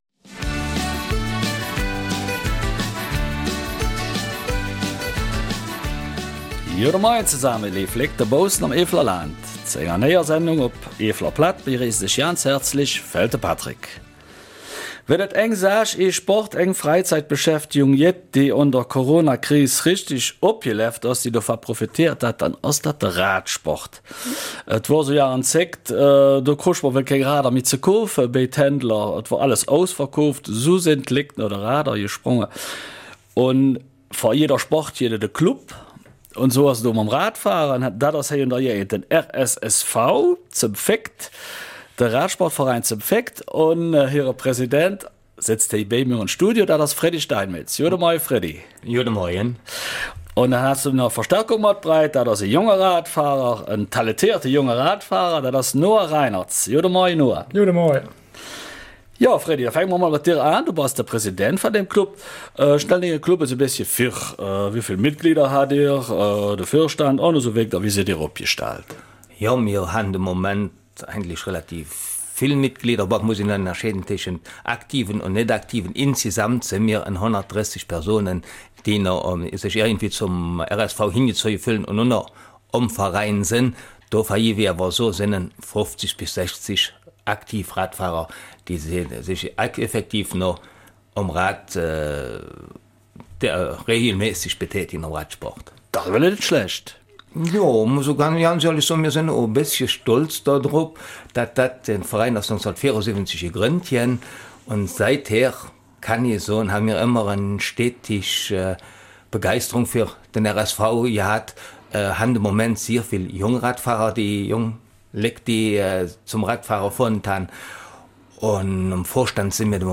Eifeler Mundart: RSV St. Vith